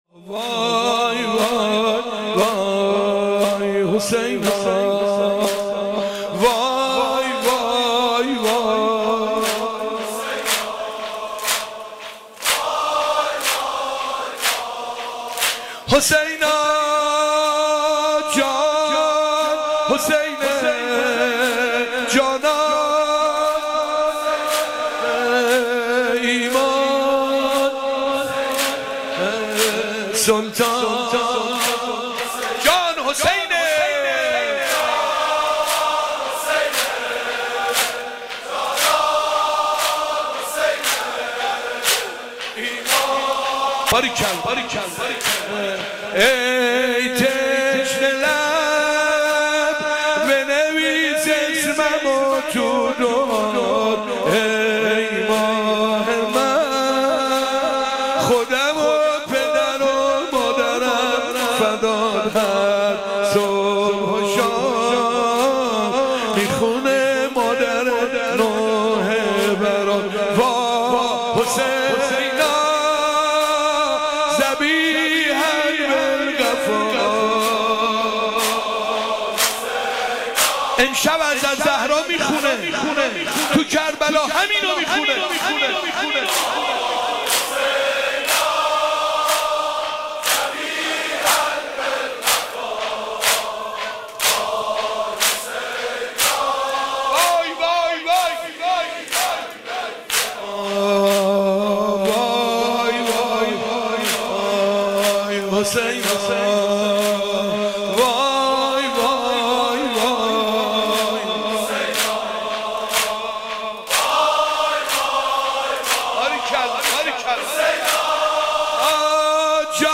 شعار شب چهارم